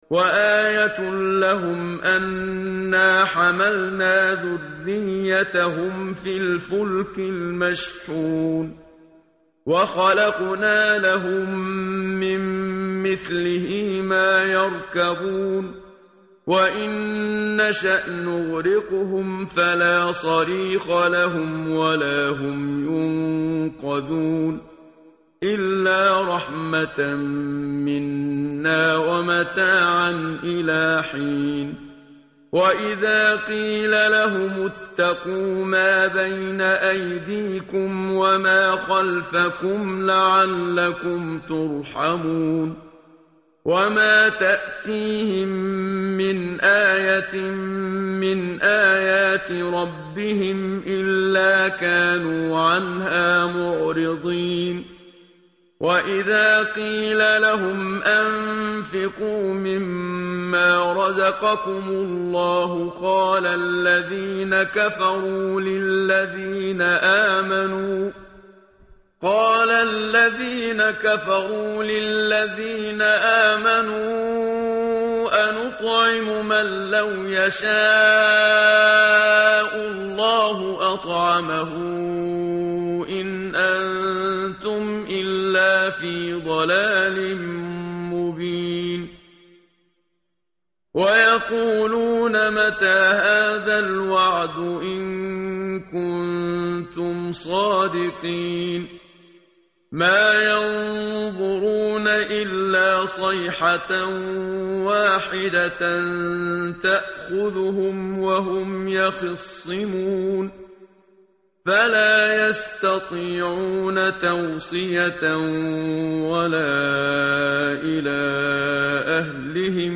متن قرآن همراه باتلاوت قرآن و ترجمه
tartil_menshavi_page_443.mp3